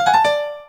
BOOP.wav